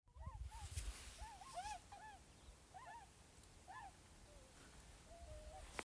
Tundra Swan, Cygnus columbianus